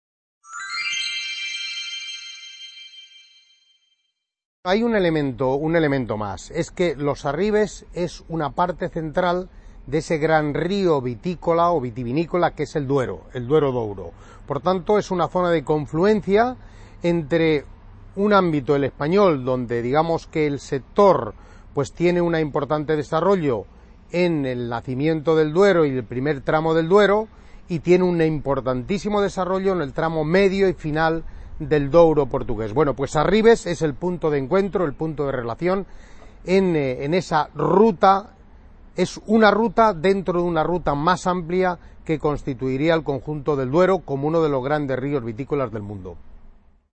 C.A. Ponferrada - II Congreso Territorial del Noroeste Ibérico